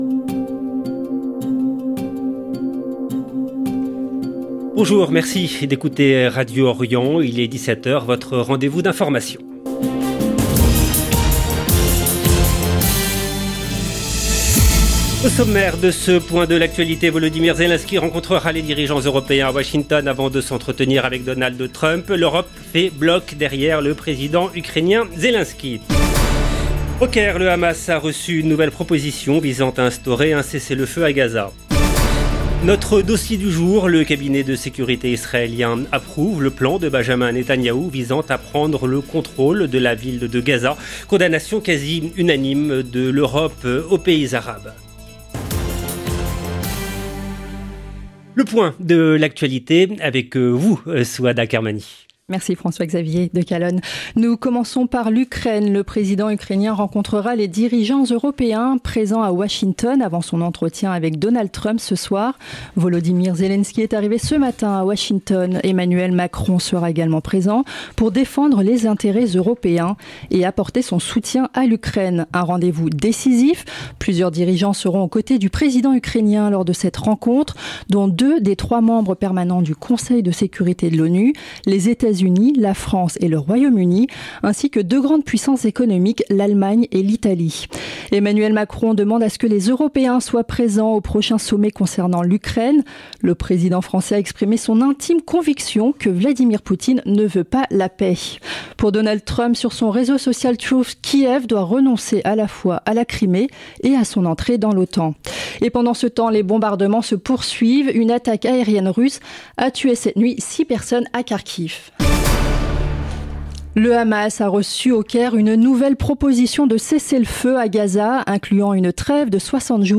Magazine d'information de 17H du 18 août 2025